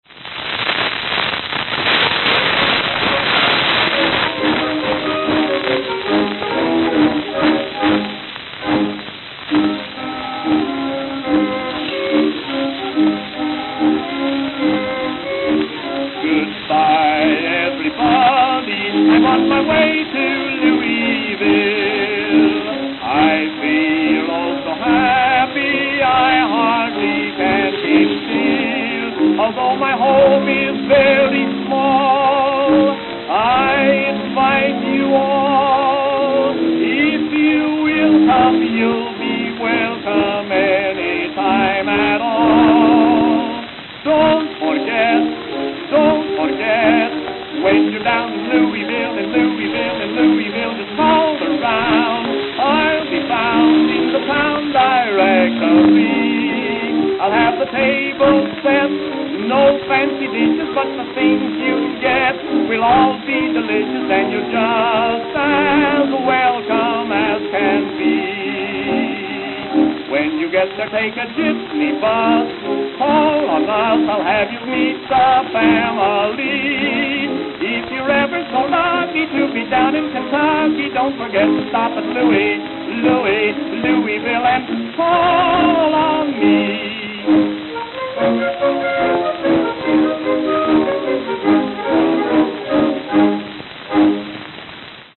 Tenor Solo
Note: Worn, especially at start.